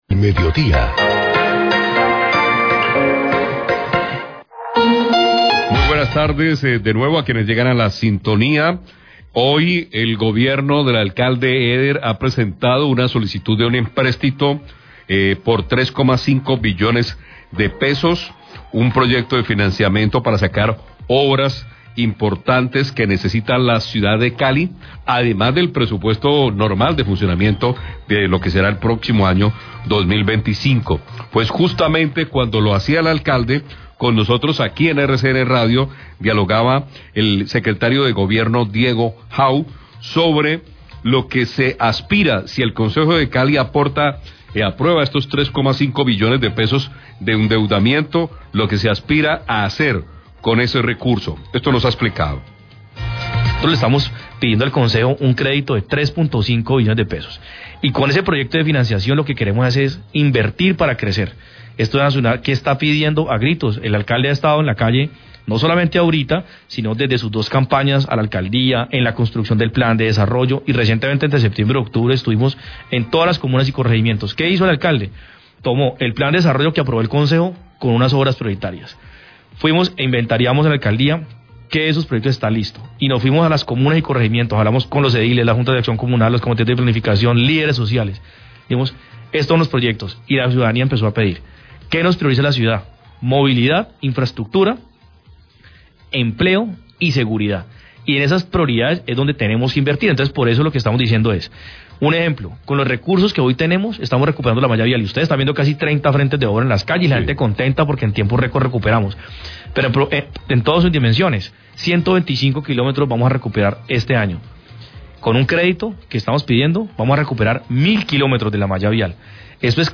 Radio
Alcalde de Cali presentó hoy al Concejo de Cali el proyecto de acuerdo para solicitar un empréstito de 3.5billones de pesos. El secretario de Gobierno explicó en qué consiste y los proyectos que se pretenden desarrollar con esos recursos.